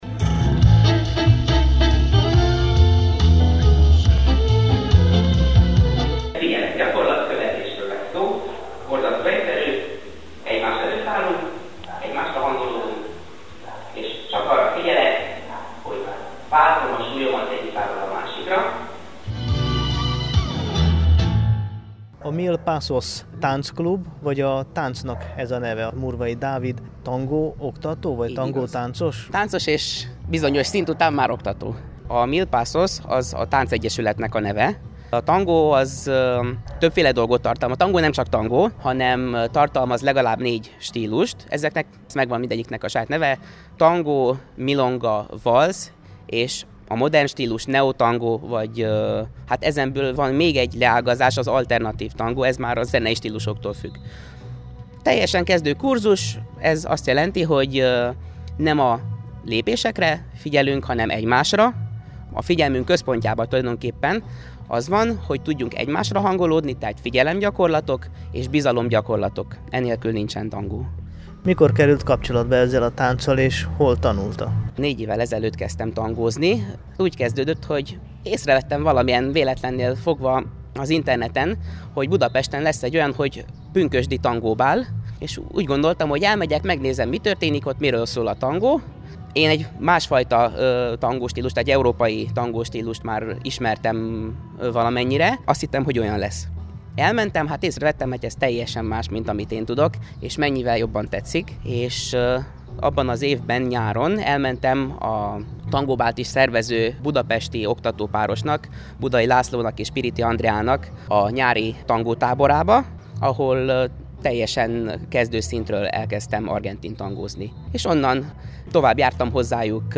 mil_pasos_tangoklub.mp3